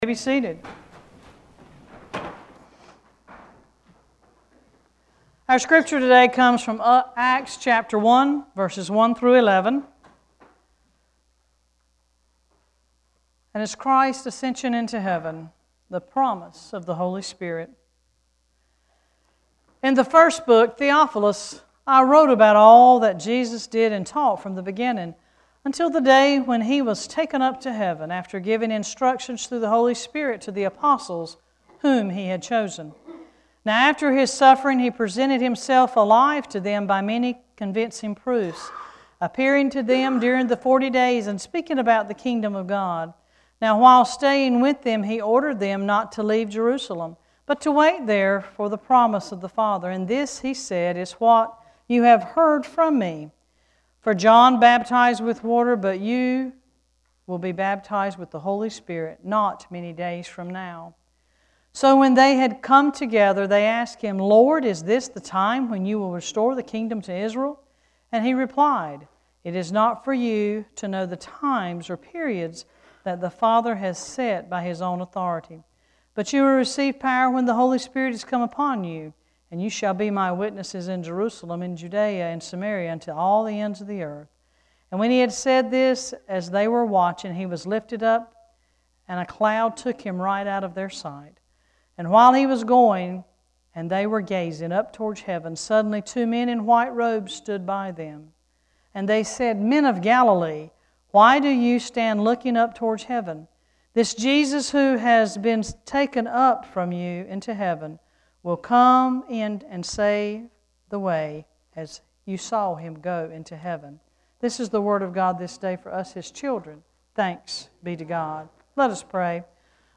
Worship Service 6-1-14: Ascended into Heaven